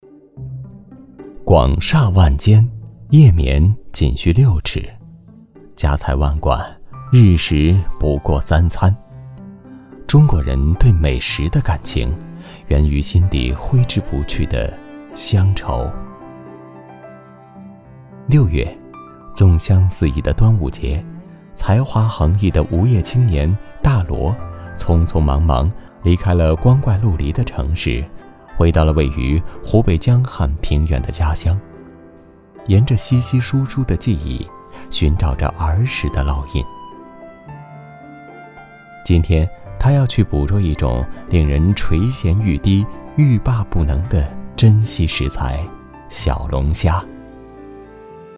轻松自然 舌尖美食
年轻磁性男音，声线很适合新闻腔。